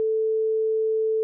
A 440Hz tone
440Hz.wav